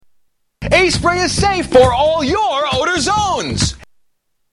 Tags: Media Doc Bottoms Aspray Doc Bottoms Aspray Ads Doc Bottoms Aspray Commercial Body Deodorant